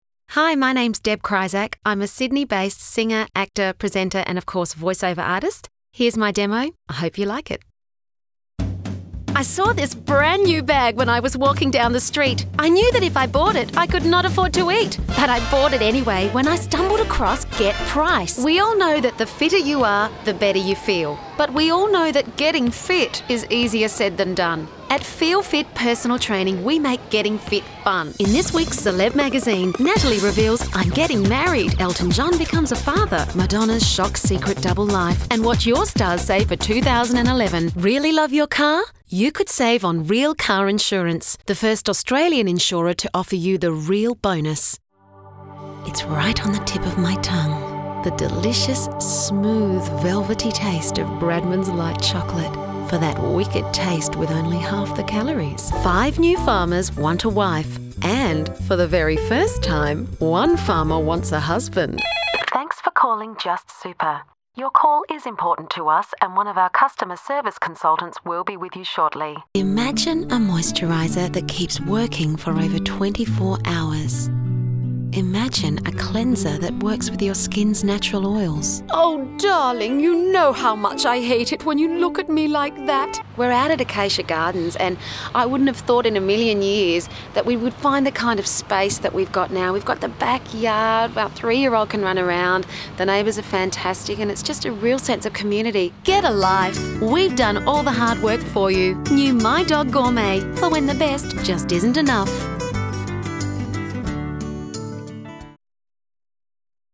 VOICE OVER
voiceoverreel.mp3